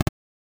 8 Bit Click (2).wav